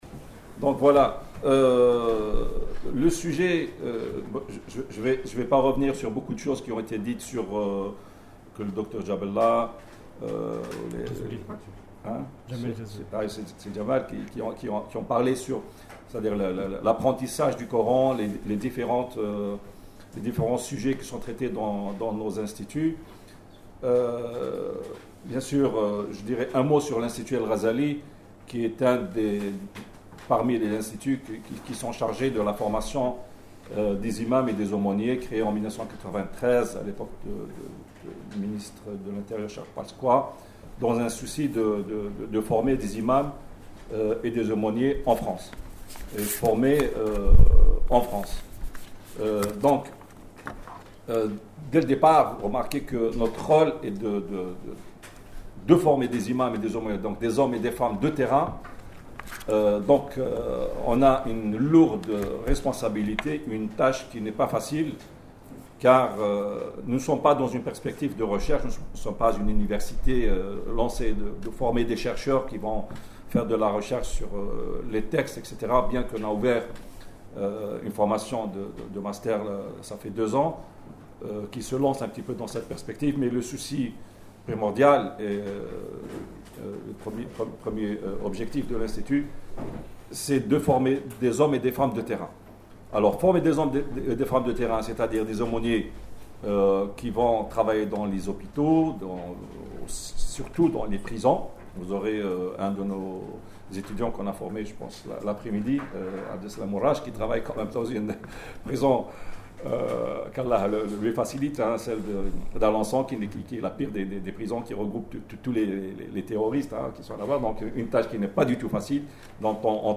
Accédez à l'intégralité de la conférence en podcast audio dans l'onglet téléchargement